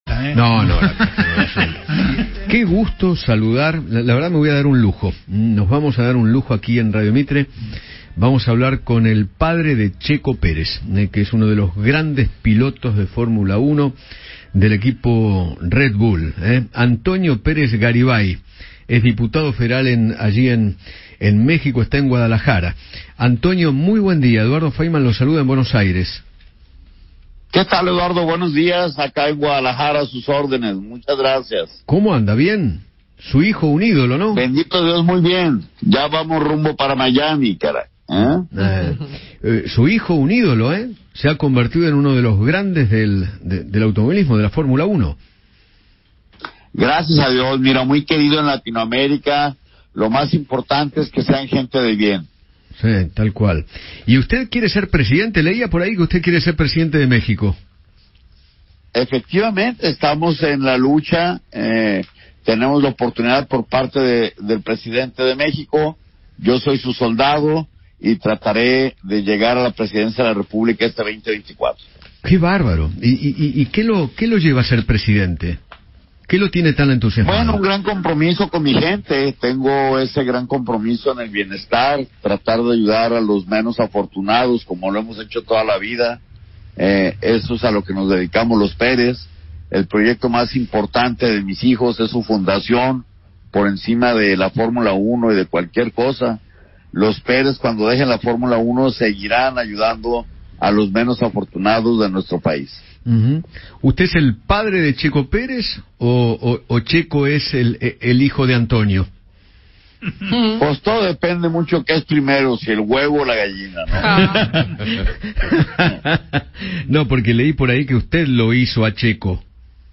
Antonio Pérez Garibay, diputado mexicano y padre del piloto de Fórmula 1 Checo Pérez, charló con Eduardo Feinmann sobre su hijo, sus intenciones de convertirse en el presidente de México en 2024 e incluso se refirió a la situación actual de ese país.